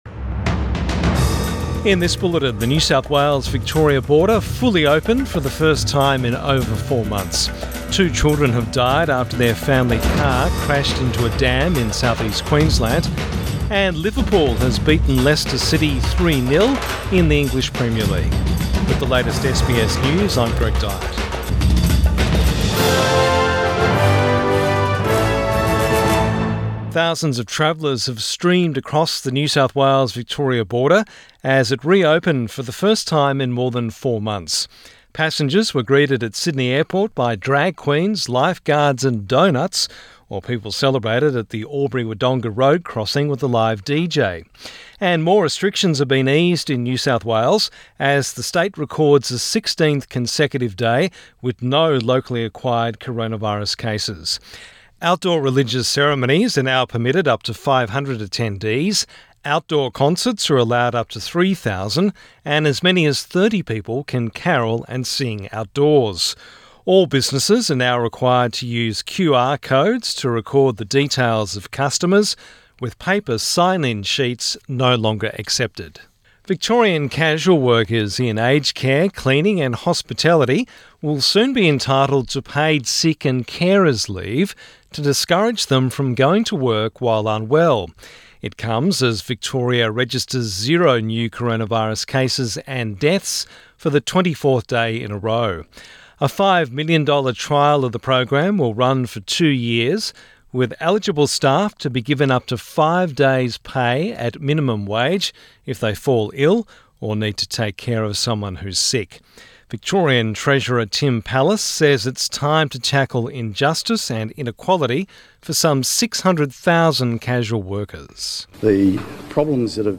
PM bulletin 23 November 2020